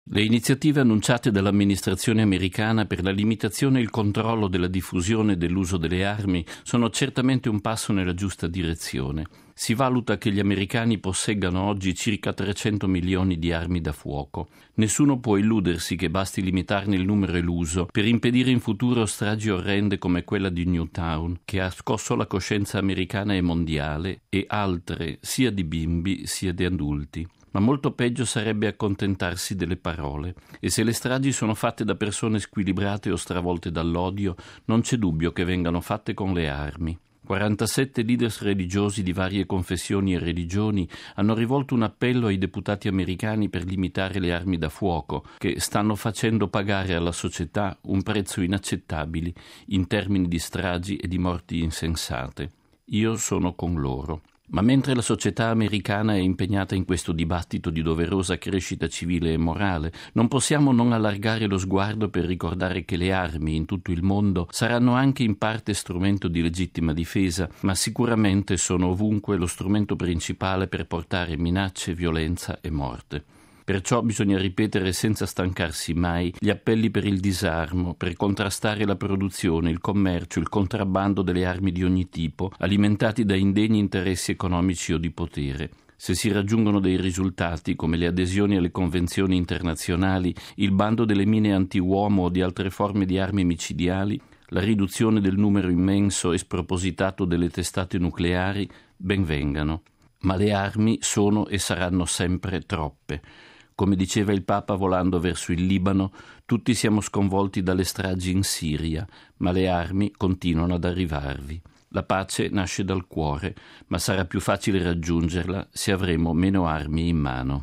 Ascoltiamo in proposito il nostro direttore, padre Federico Lombardi, nel suo editoriale per Octava Dies, il Settimanale informativo del Centro Televisivo Vaticano:RealAudio